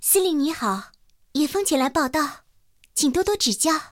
野蜂登场语音.OGG